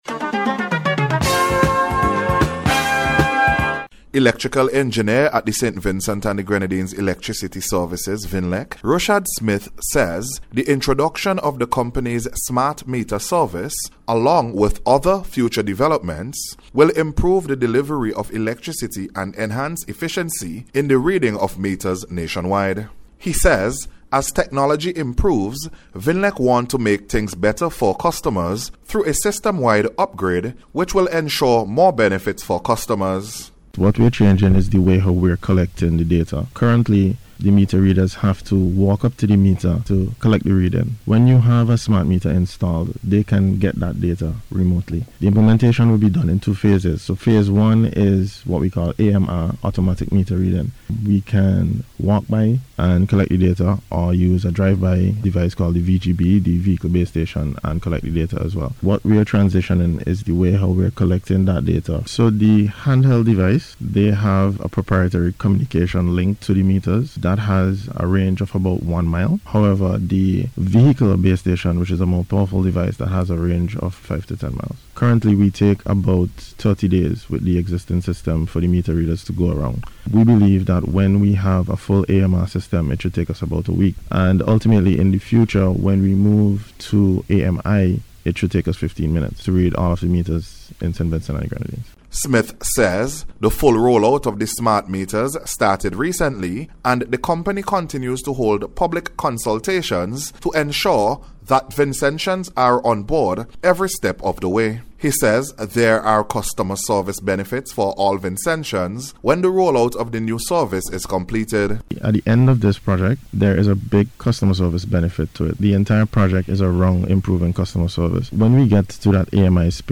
ENHANCING-ELECTRICITY-SERVICES-REPORT.mp3